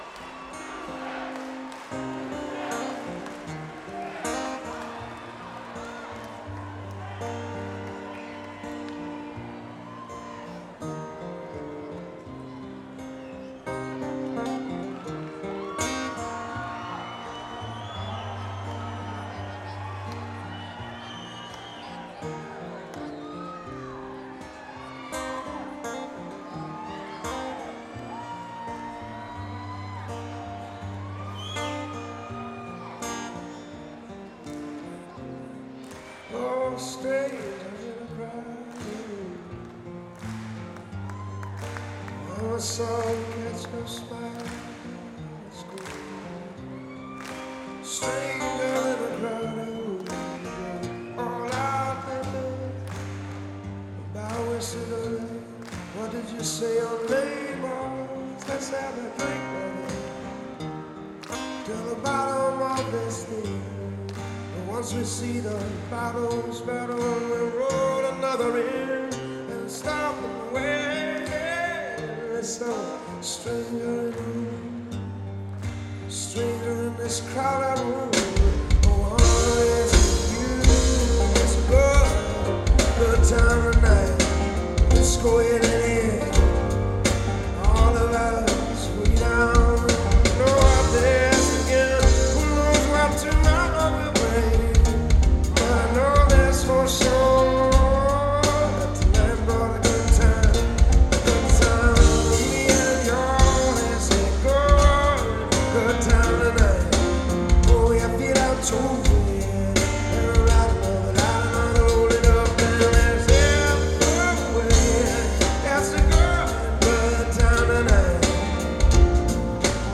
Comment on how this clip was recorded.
7.21.2004 Tweeter Center at the Waterfront, Camden, NJ 8:02